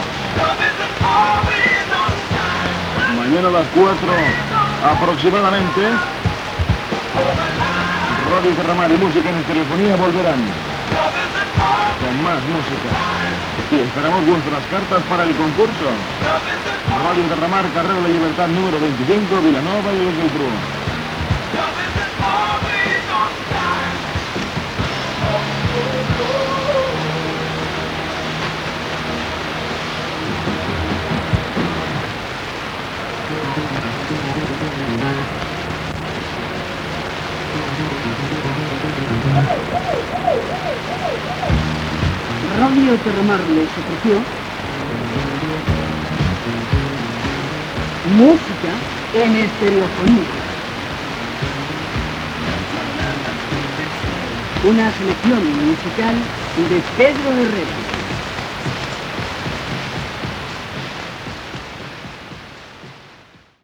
Final del programa i careta de sortida.
FM